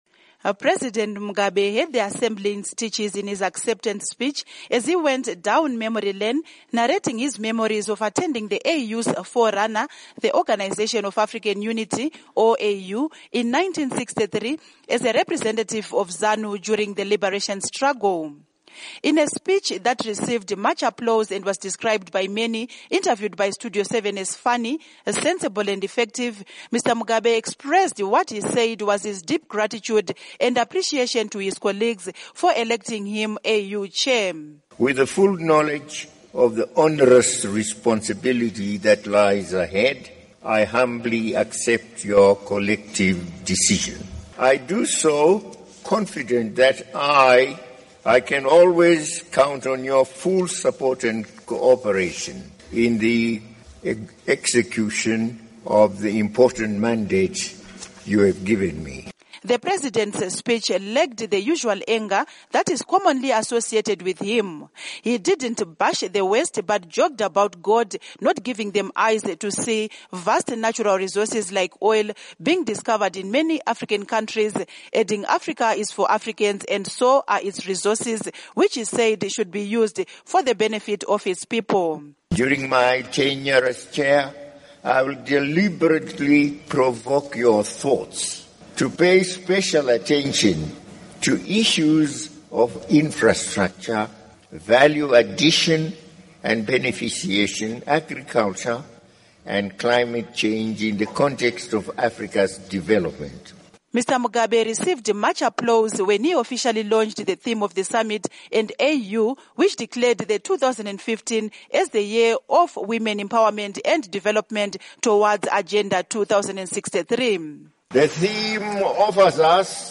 AU Summit Report